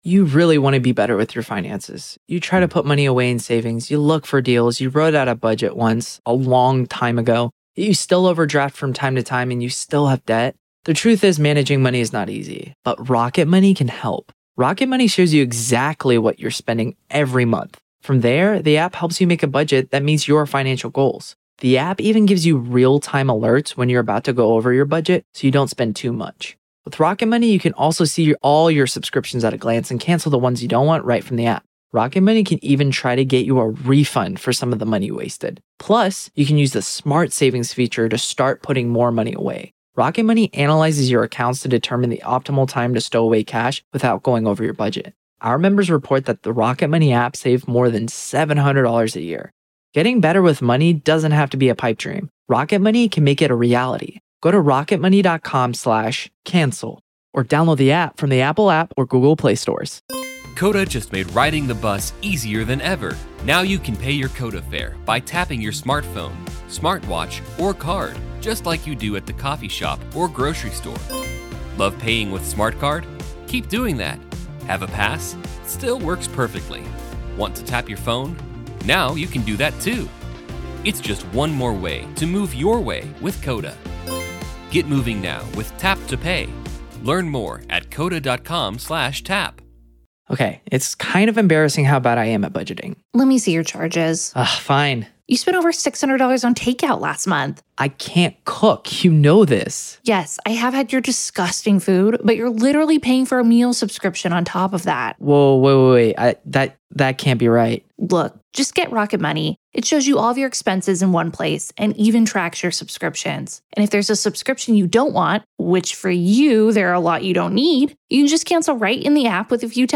Every episode beams you directly into the heart of the courtroom, with raw, unedited audio from testimonies, cross-examinations, and the ripple of murmurs from the gallery.